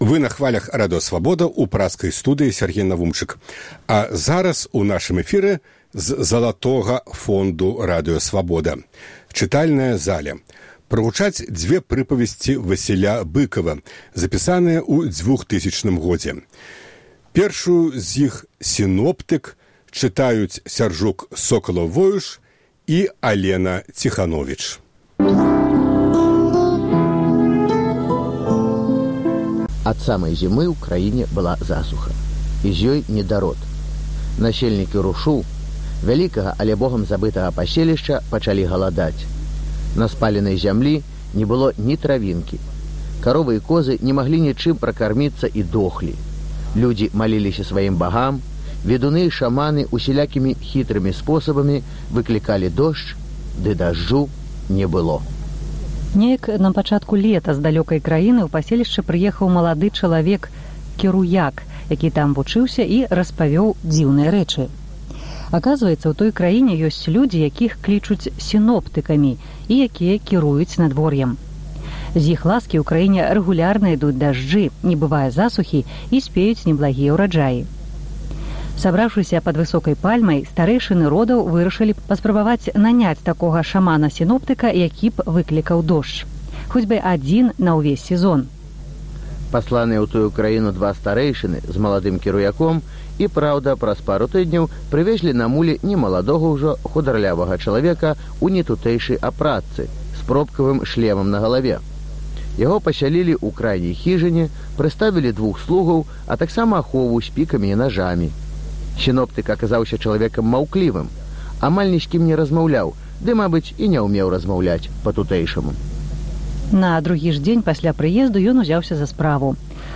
У чытальнай залі Свабоды - прыпавесьці ВАсіля Быкава, які ён дасылаў ў Прагу зь Нямеччыны. Сёньня - "Сыноптык" і "Звон".